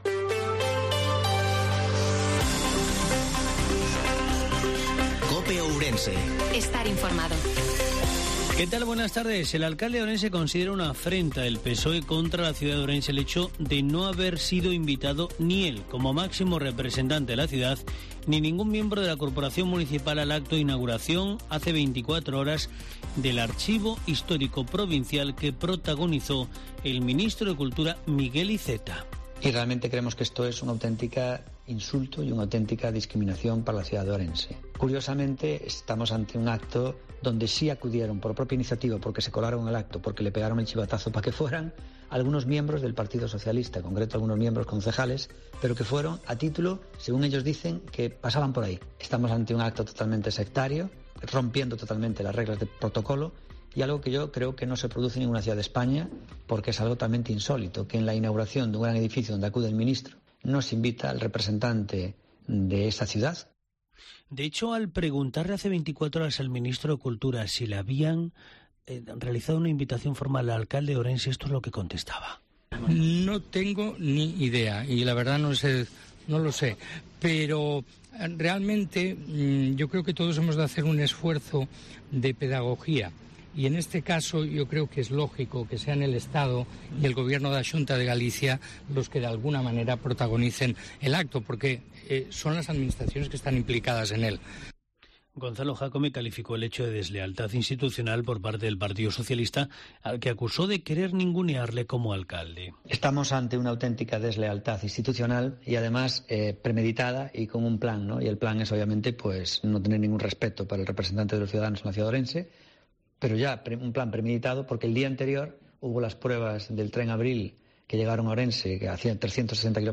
INFORMATIVO MEDIODIA COPE OURENSE-09/09/2022